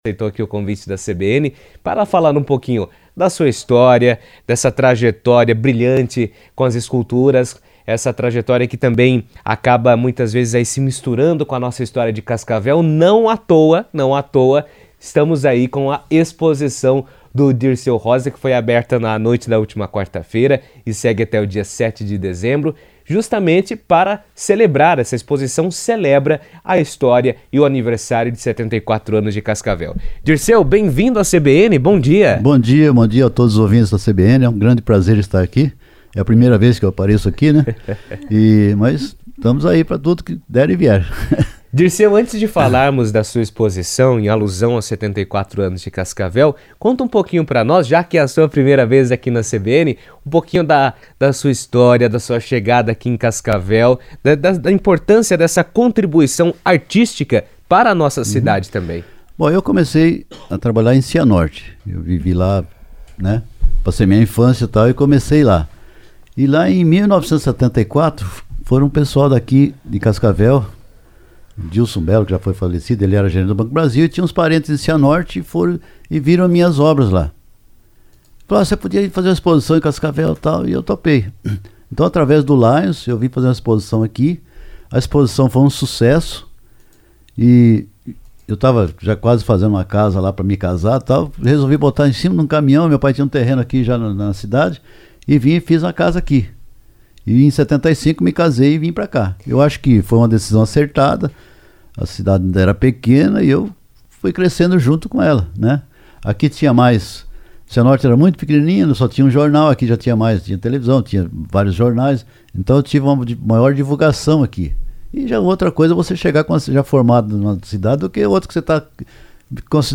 Em entrevista à CBN, ele comentou sobre a inspiração por trás das esculturas e destacou a importância de celebrar o aniversário de Cascavel por meio da arte, que permite ao público conhecer e se conectar com a memória e a cultura local.